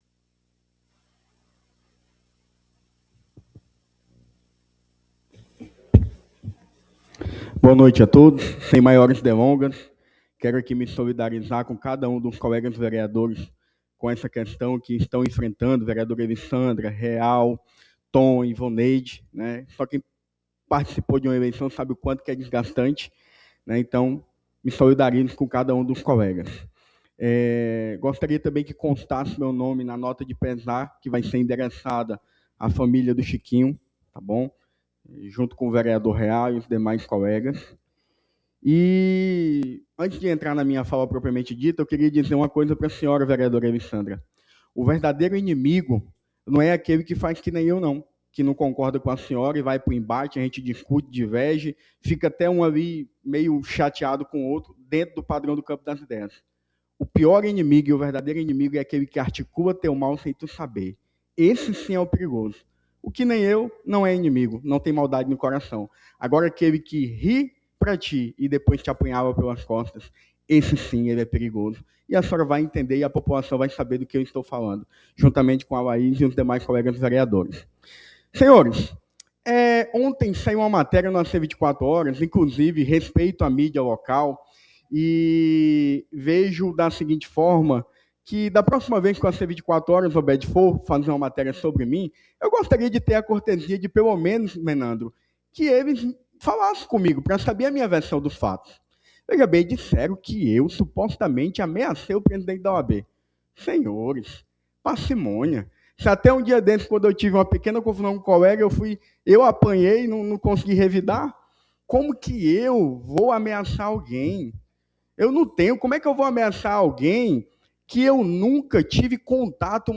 Na tribuna, o parlamentar afirmou que recebeu a notícia com surpresa e criticou o fato de não ter sido procurado para apresentar sua versão antes da publicação. Segundo ele, a informação divulgada não condiz com a realidade dos fatos.